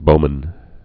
(bōmən)